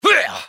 vs_fScarabx_atk2.wav